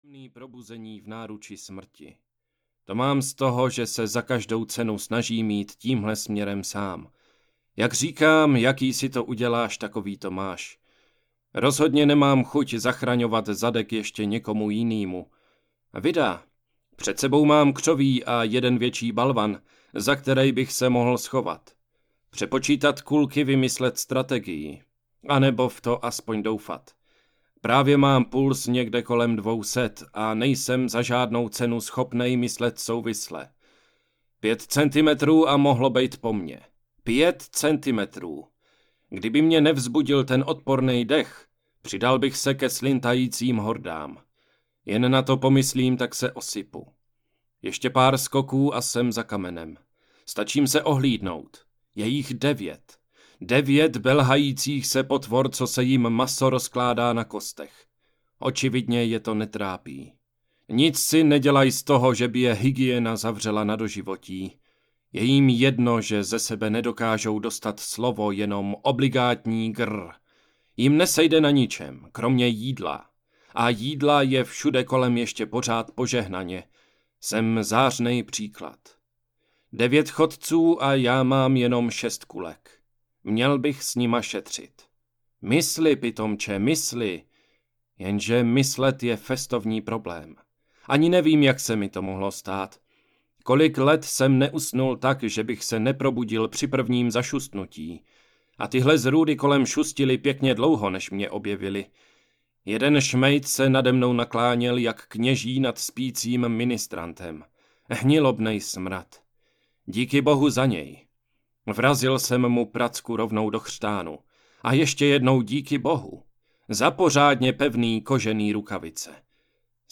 Mrtví kráčí po zemi audiokniha
Ukázka z knihy